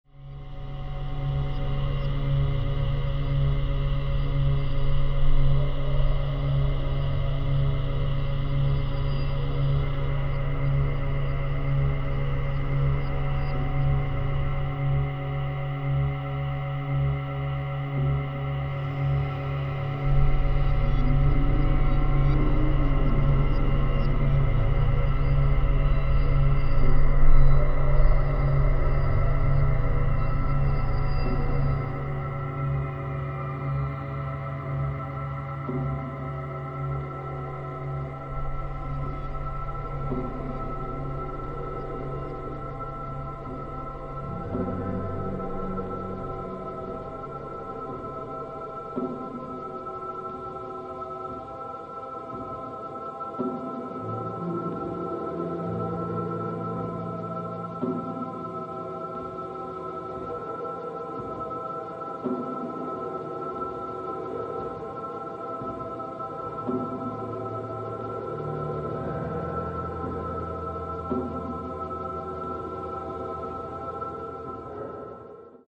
Category: deep ambient, experimental, soundscapes